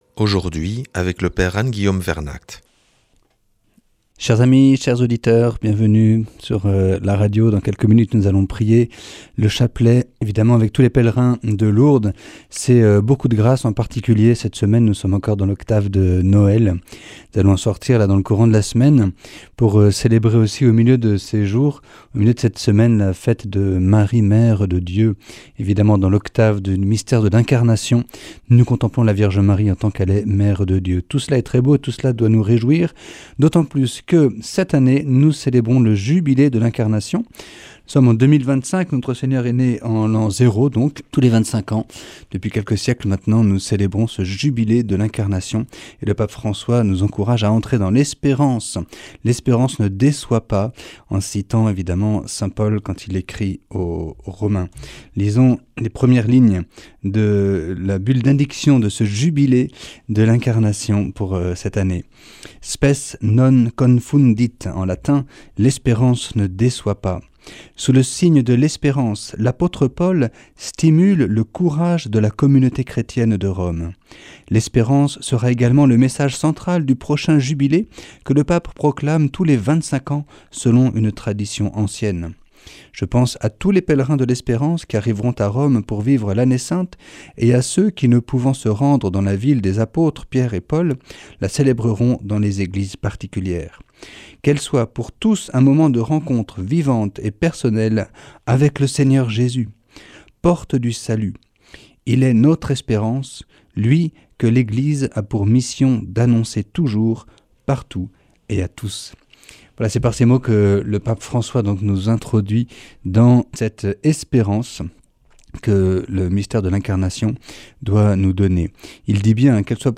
nous propose une lecture de la Bulle d’indiction du Jubilé de l’Espérance du Pape François.